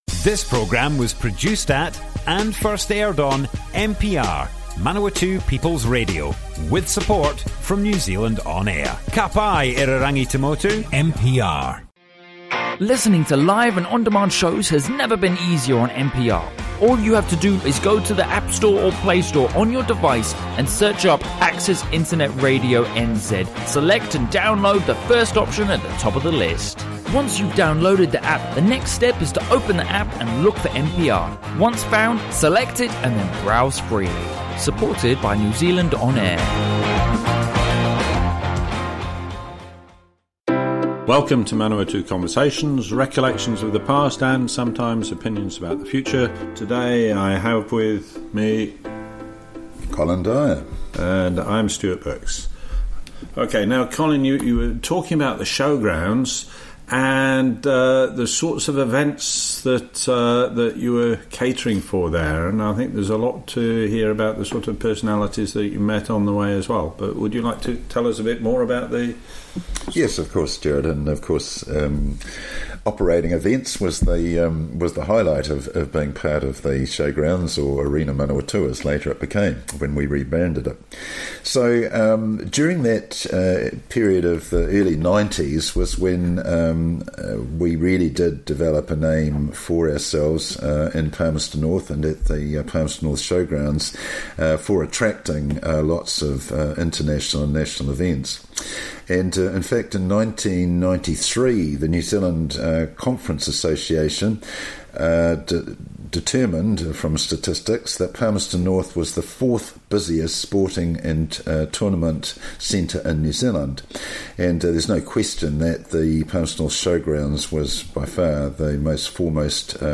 Manawatu Conversations More Info → Description Broadcast on Manawatu People's Radio 18 December 2018.
oral history